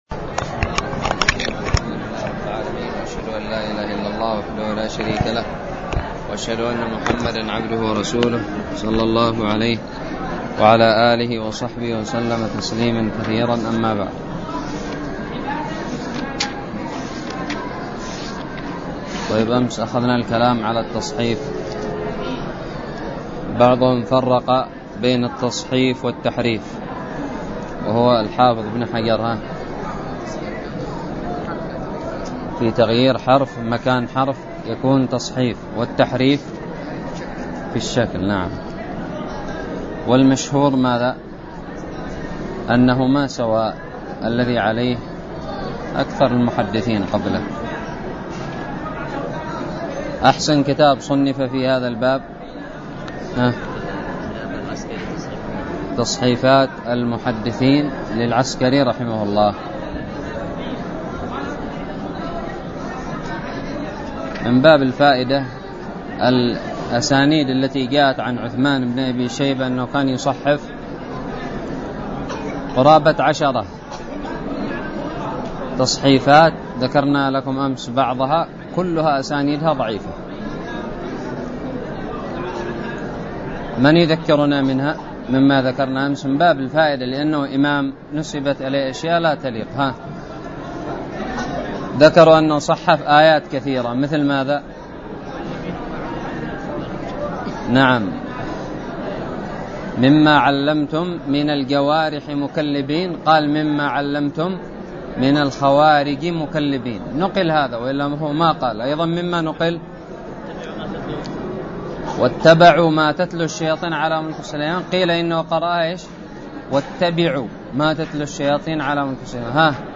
الدرس الخامس والأربعون من شرح كتاب الباعث الحثيث
ألقيت بدار الحديث السلفية للعلوم الشرعية بالضالع